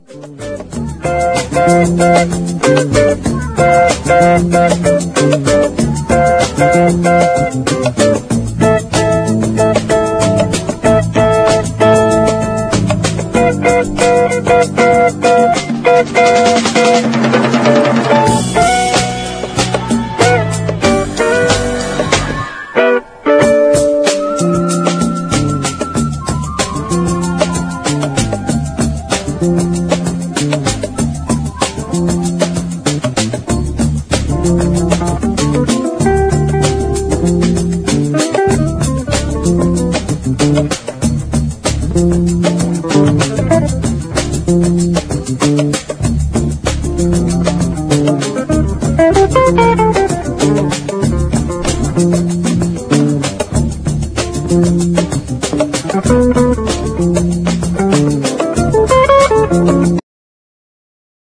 JAZZ / DANCEFLOOR / ELECTRIC PIANO